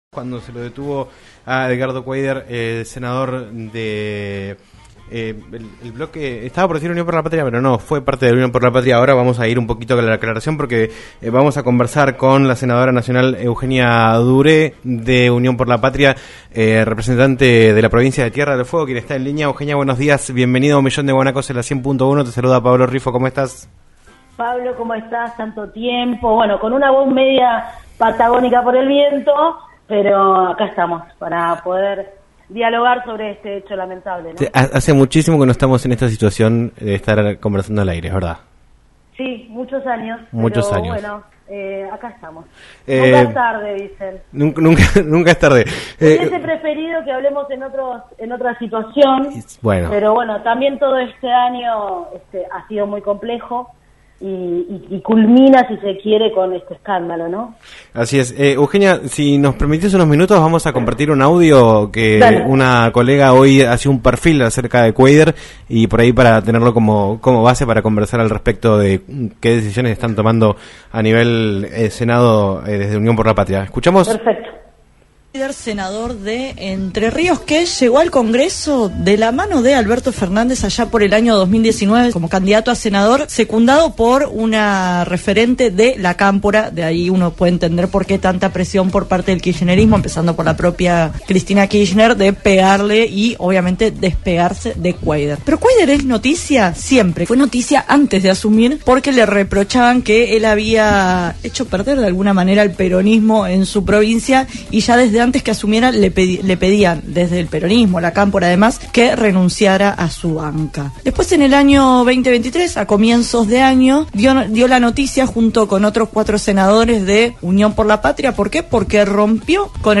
María Eugenia Duré, senadora nacional por la provincia de Tierra del Fuego del bloque Unidad Ciudadana, dialogó en "Un Millón de Guanacos" por LaCienPuntoUno sobre el caso de Edgardo Darío Kueider, senador nacional por Entre Ríos desde 2019 que fue detenido este miércoles por la madrugada en el Puente Internacional de la Amistad, que une Brasil y Paraguay, con más de 200.000 dólares en efectivo sin declarar.